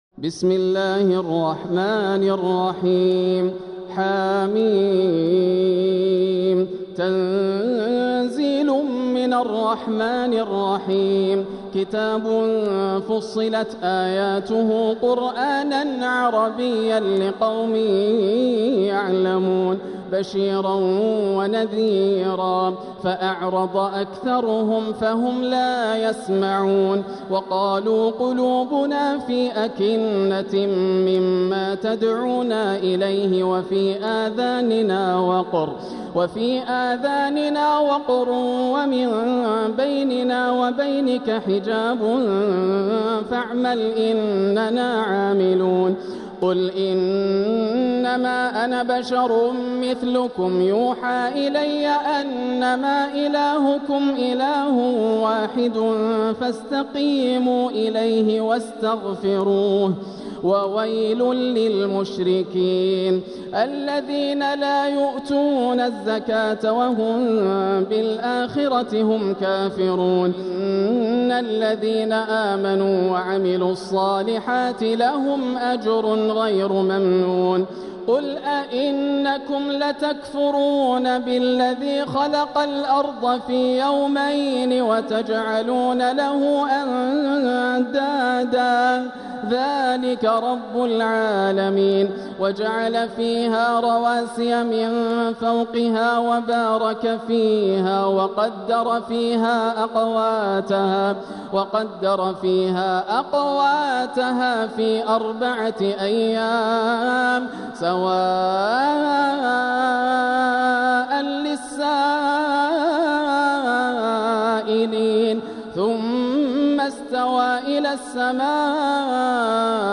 سورة فصلت | مصحف تراويح الحرم المكي عام 1446هـ > مصحف تراويح الحرم المكي عام 1446هـ > المصحف - تلاوات الحرمين